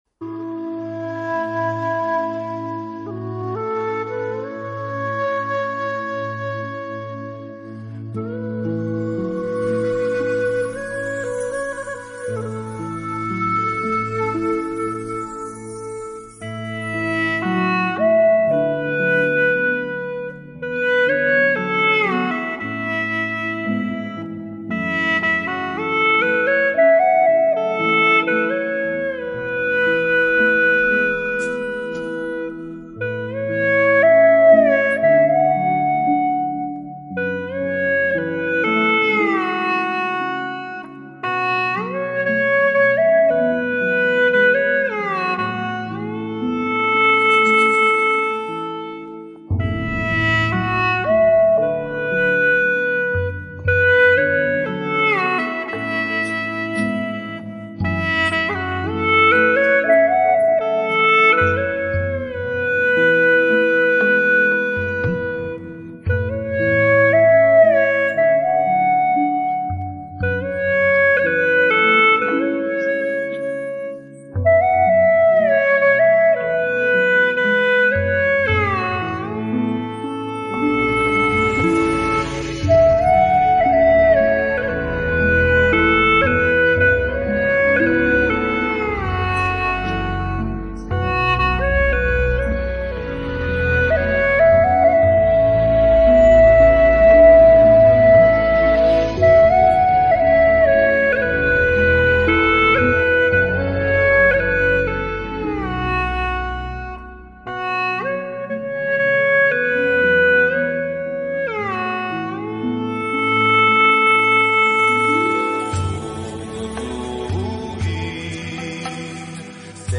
调式 : A 曲类 : 流行